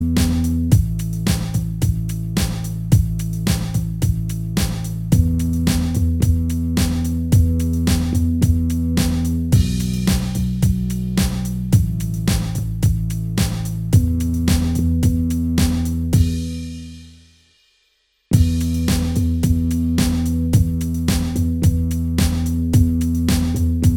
Minus Lead Guitar Rock 4:09 Buy £1.50